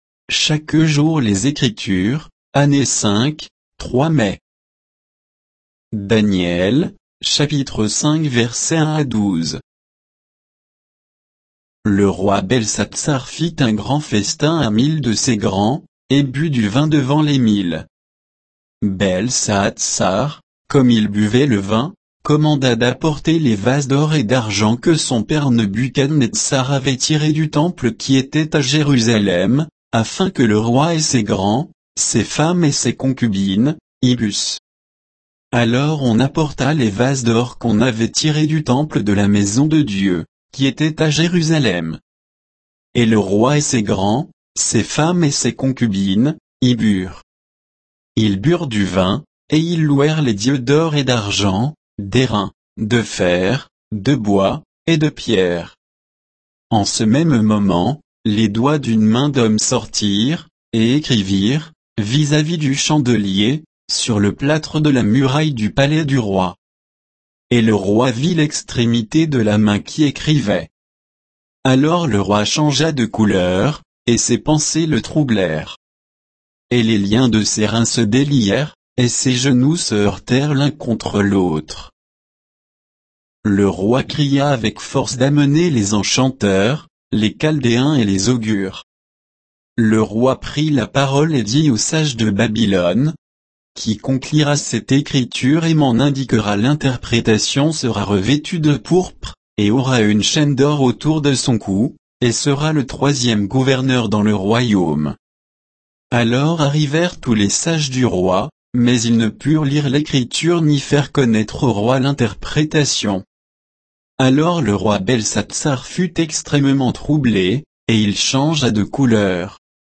Méditation quoditienne de Chaque jour les Écritures sur Daniel 5, 1 à 12